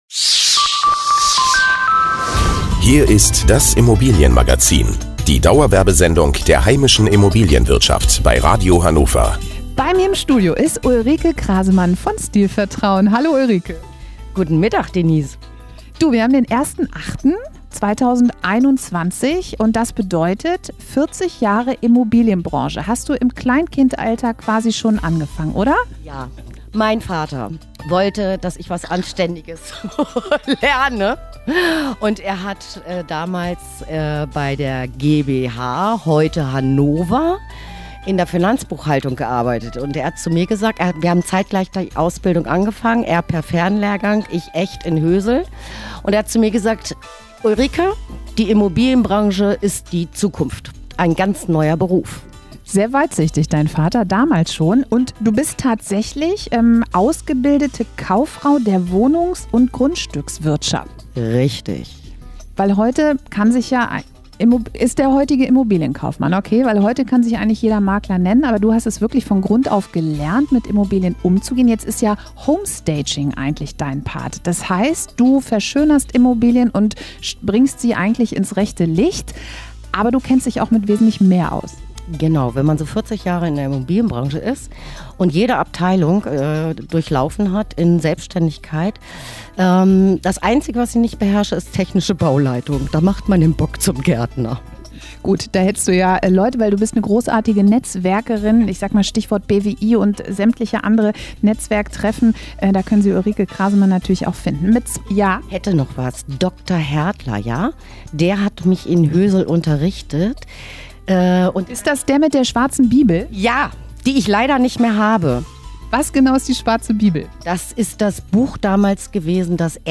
Radiobeitrag vom 01.08.2021: 40 Jahre im Geschäft
Ich machte mein Hobby zum Beruf und wurde zur Netzwerkerin mit Herz und Seele. Mehr hört Ihr in diesem Interview bei Radio Hannover: